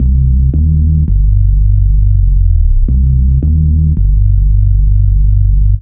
Index of /90_sSampleCDs/Zero-G - Total Drum Bass/Instruments - 1/track02 (Bassloops)